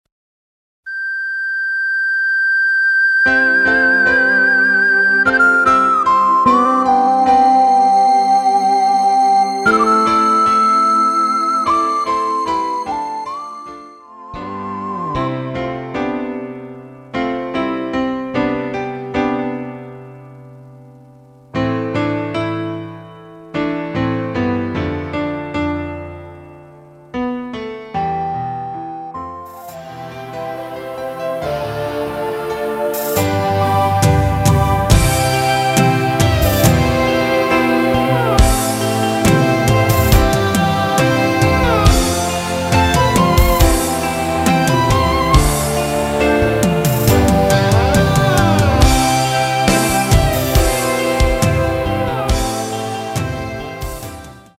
원키에서(-10)내린 MR입니다.
원곡의 보컬 목소리를 MR에 약하게 넣어서 제작한 MR이며